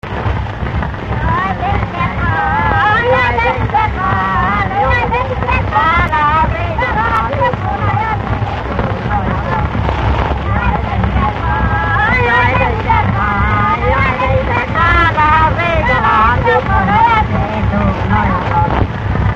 Dallampélda: Jaj, de hideg van Alföld - Békés vm. - Pusztaföldvár Gyűjtő: Vikár Béla Gyűjtési idő: ismeretlen Médiajelzet: MH-0039b Hangfelvétel: letöltés Szöveg: Jaj, de hideg van, Jaj, de hideg van, Jaj, de hideg van, az ég alatt!